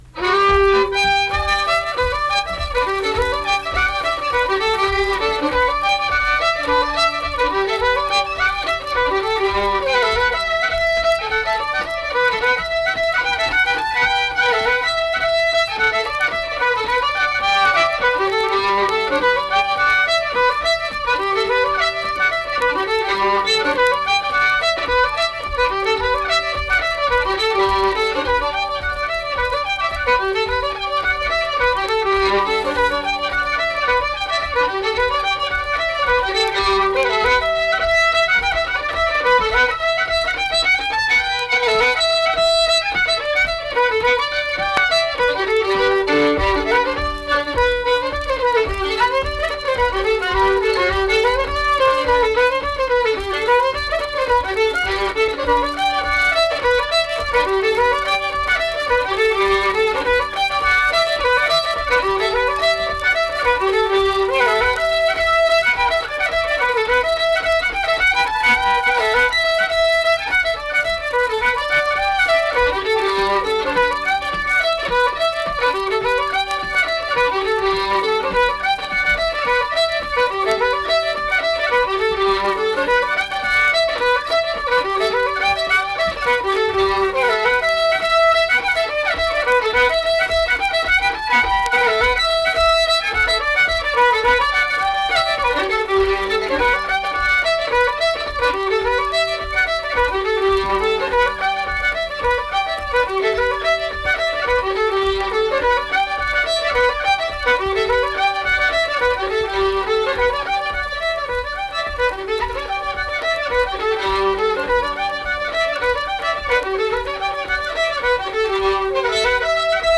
Accompanied guitar and unaccompanied fiddle music performance
Instrumental Music
Fiddle